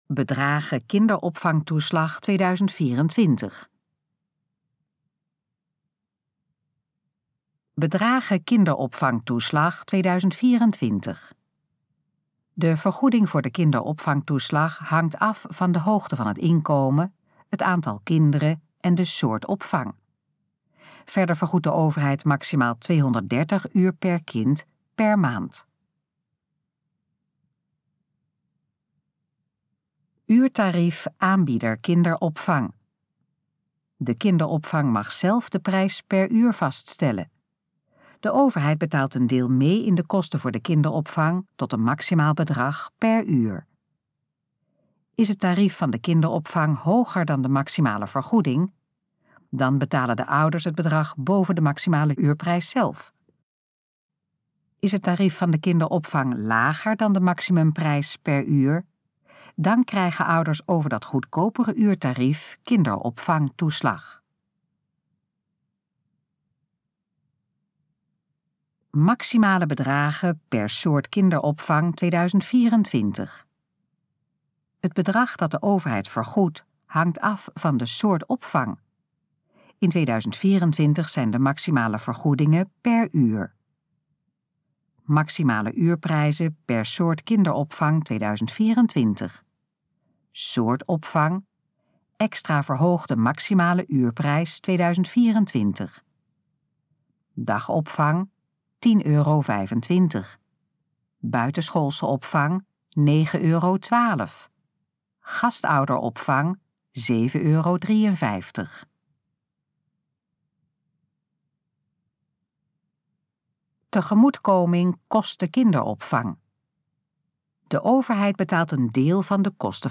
Gesproken versie van: Bedragen kinderopvangtoeslag 2024
Dit geluidsfragment is de gesproken versie van de pagina Bedragen kinderopvangtoeslag 2024